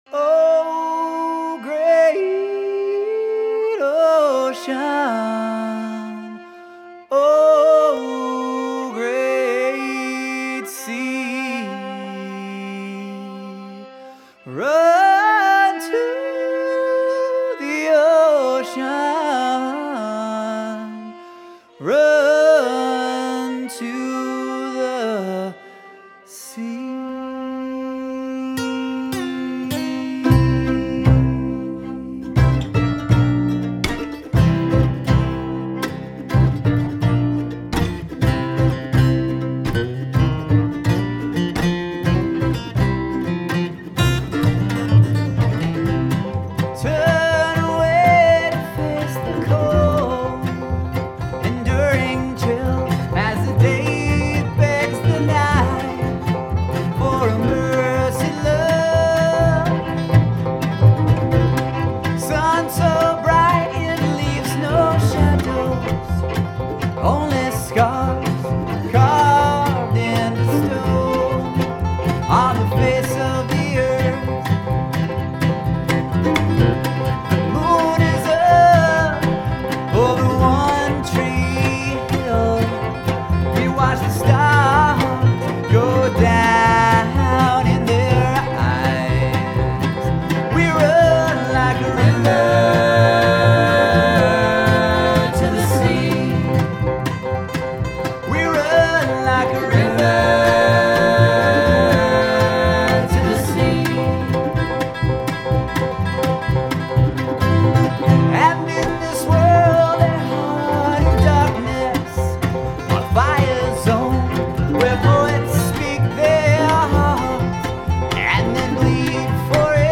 A Cover